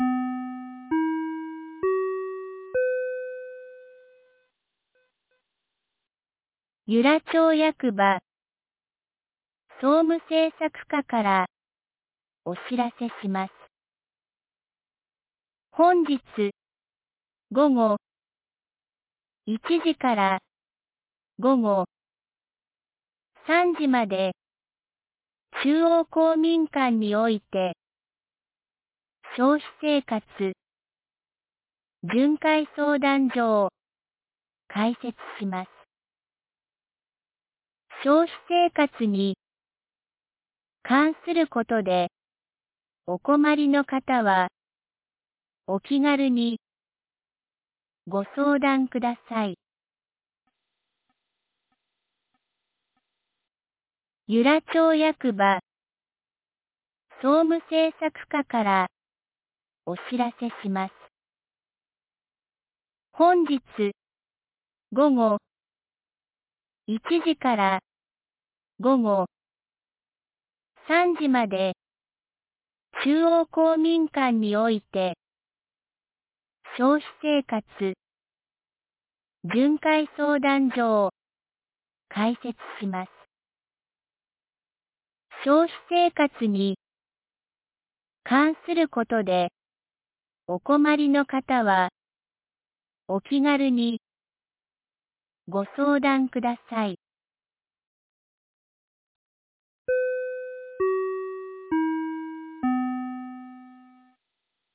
2026年02月03日 12時32分に、由良町から全地区へ放送がありました。